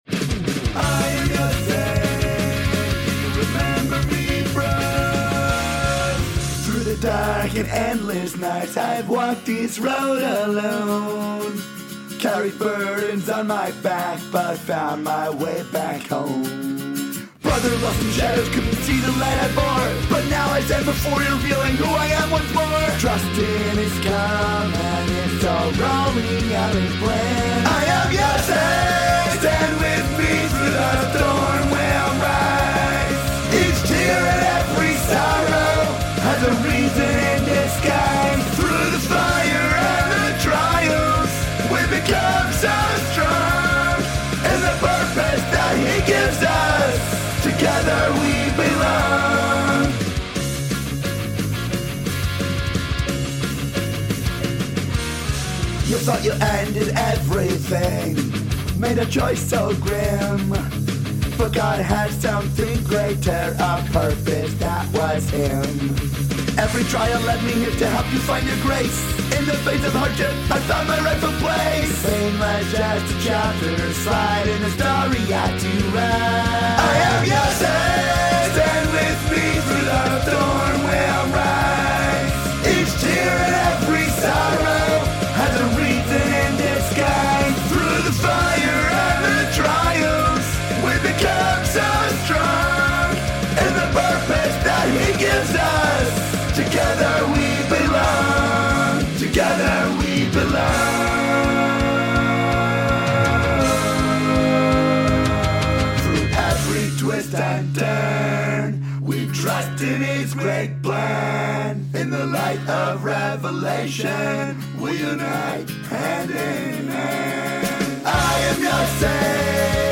This week's Rockindacious Torah Tune!